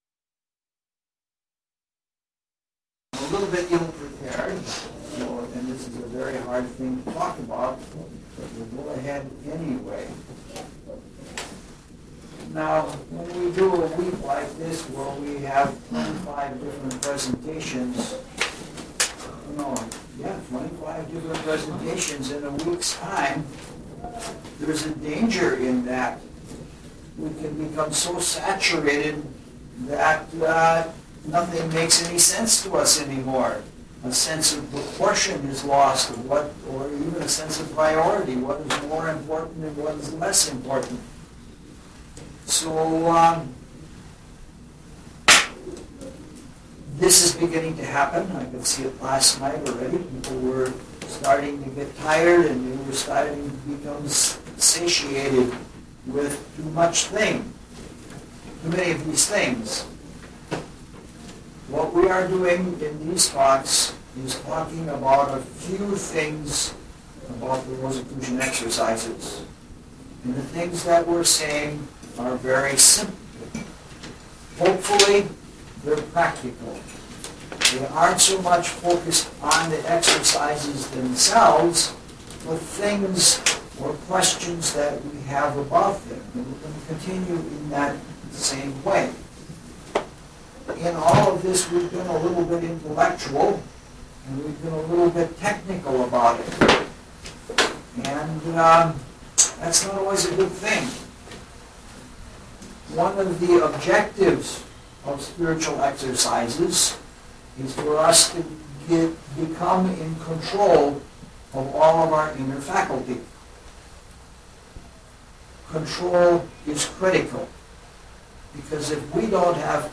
LECTURE SERIES